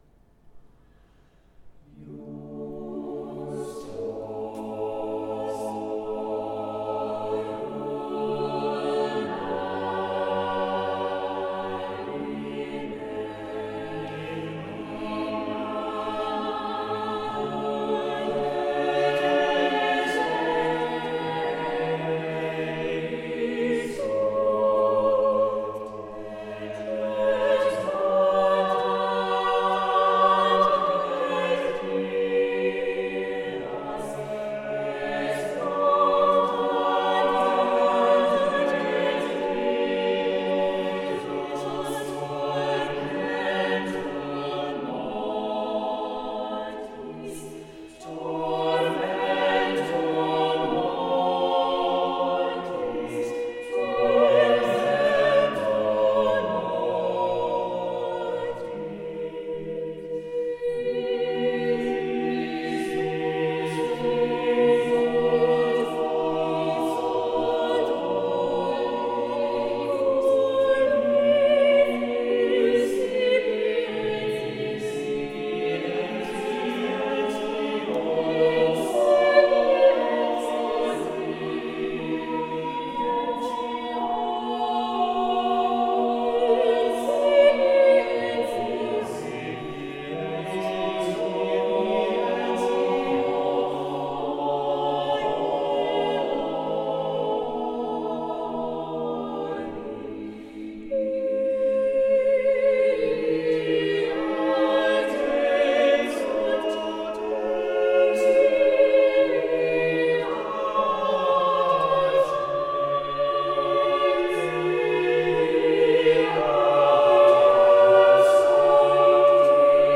Live Recordings
The recordings are not professional quality, and were taken with a single microphone during a live performance.